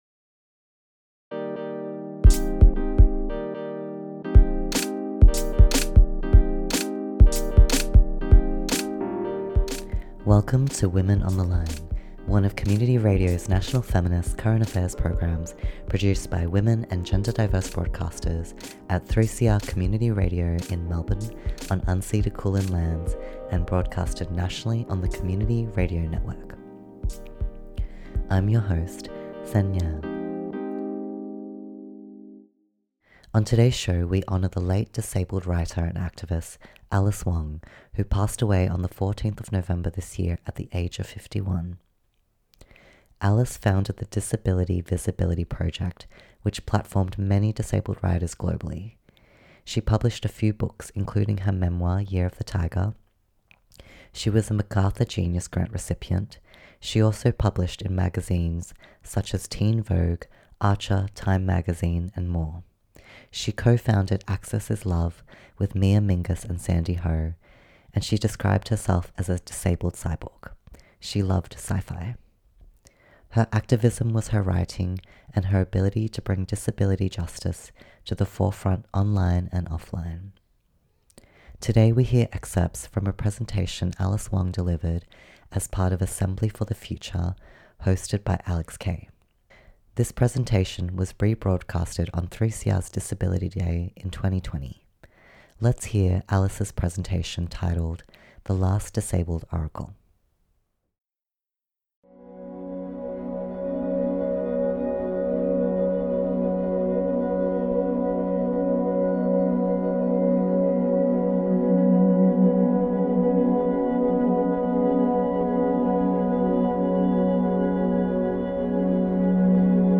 We’re listening to her presentation titled ‘The Last Disabled Oracle’ from 2020, where she speaks from the future of 2029.